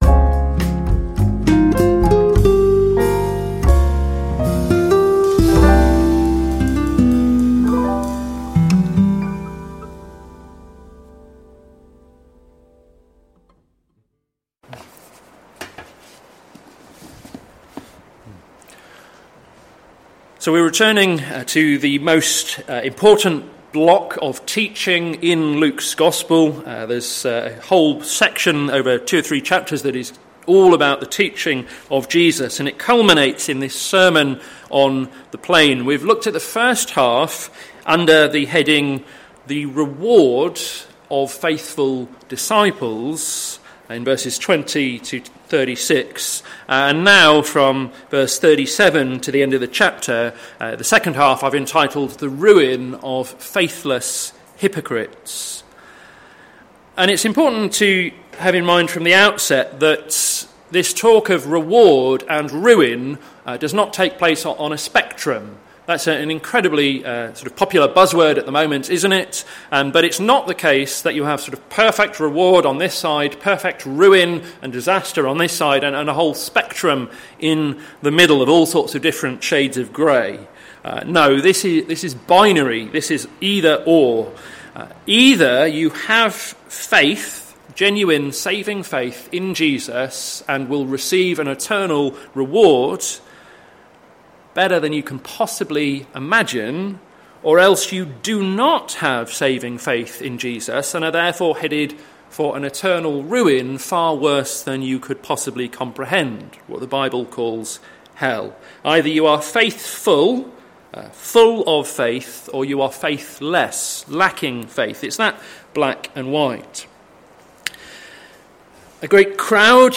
Sermon Series - To Seek and to Save the Lost - plfc (Pound Lane Free Church, Isleham, Cambridgeshire)